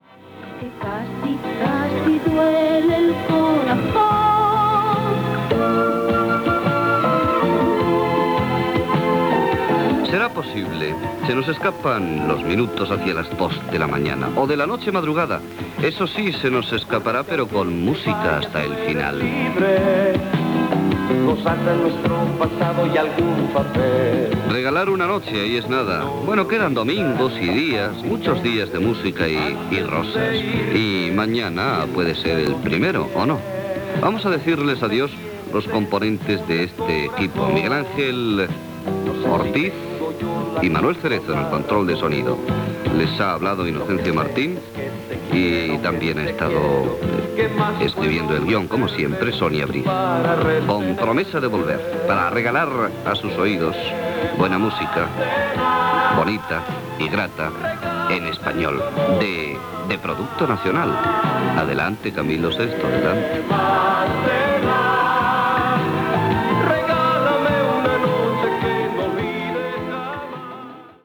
Identificació de l'emissora com Radio 1, presentació inicial i tema musical.
Tema musical, comait del programa, amb els noms de l'equip, i tema musical
Musical